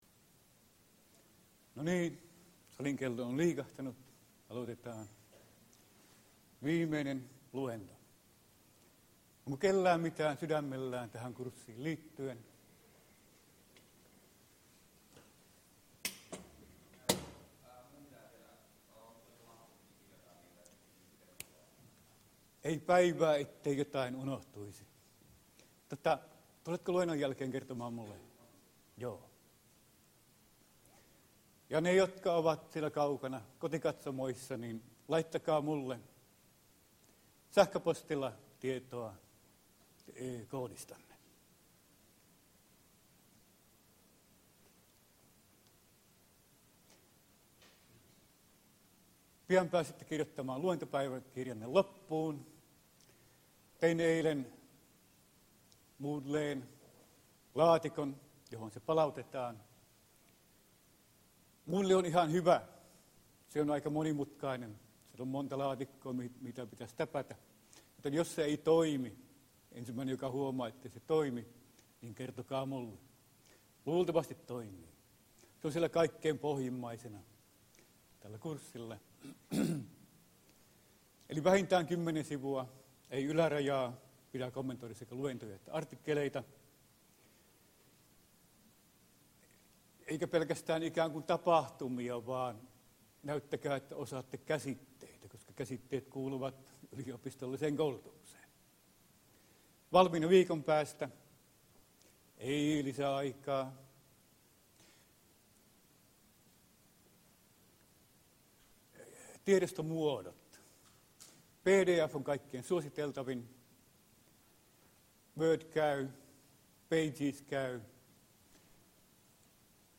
POLS3017 Luento 10 ae69e16ba20b43d3855cd95df20b719a